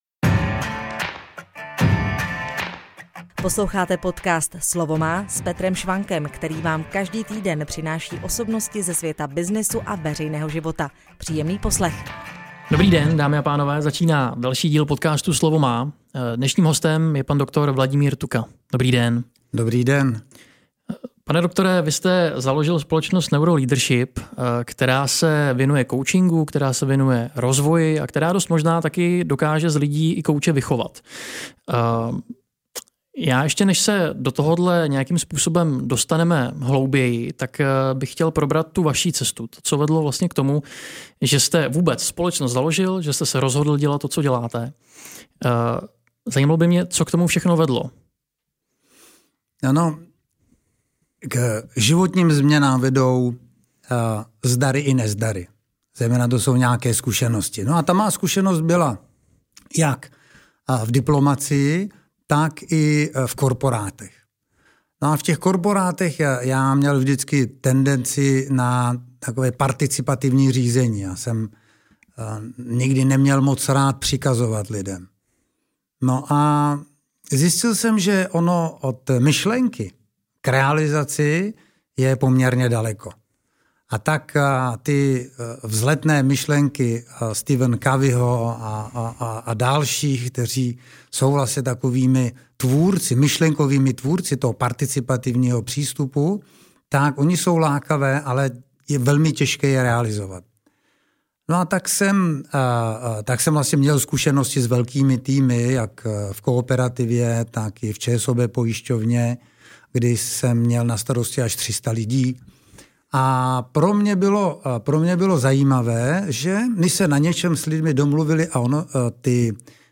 I o tom bude tento rozhovor.